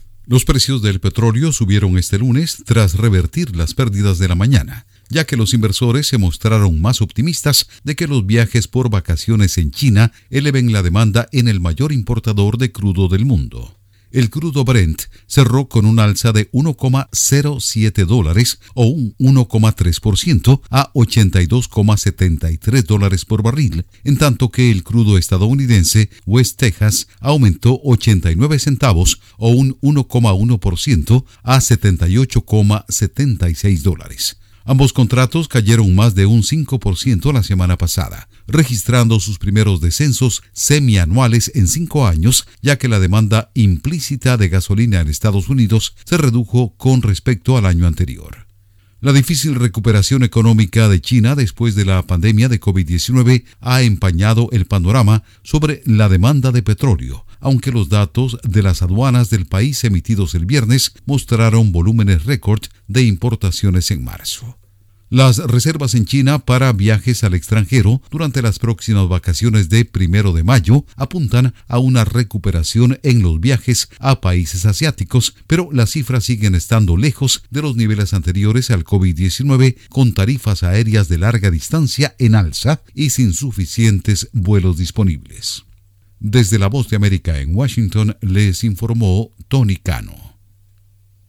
Los precios del crudo suben por optimismo sobre demanda china. Informa desde la Voz de América en Washington